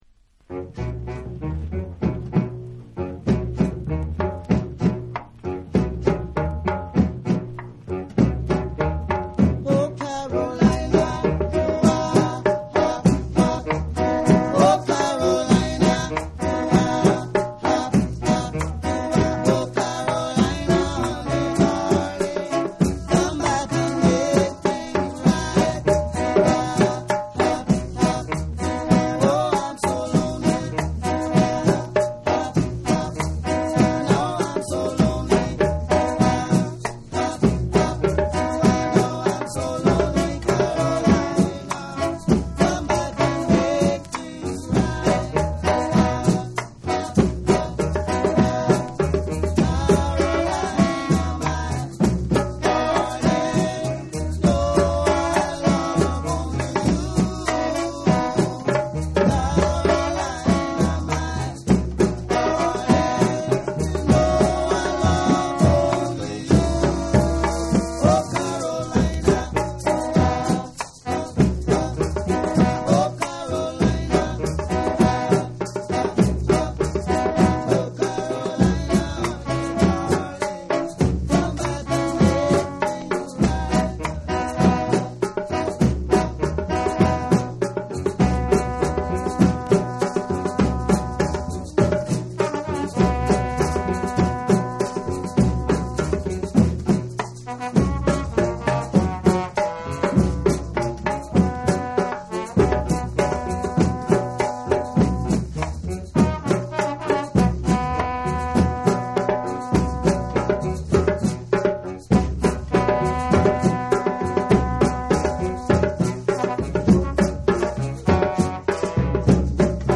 ジャズやアフロの要素も取り入れたナヤビンギ・ジャズ・インストルメンタル！！